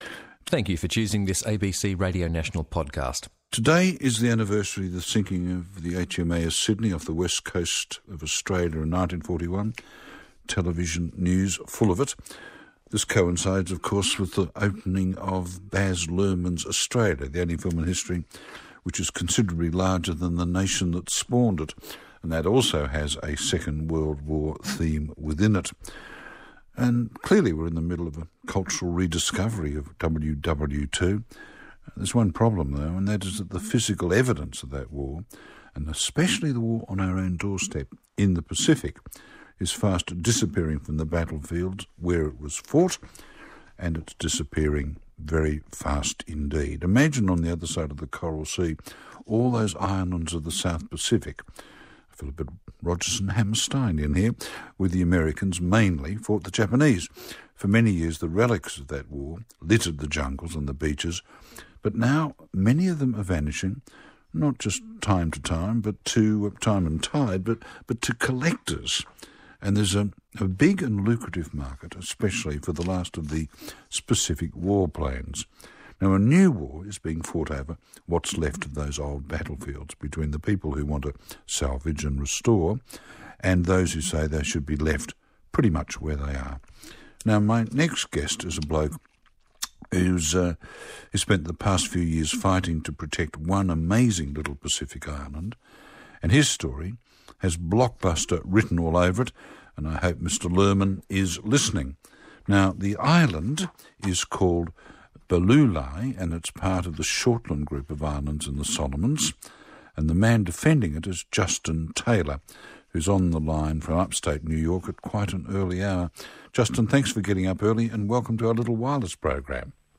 ABC Radio Interview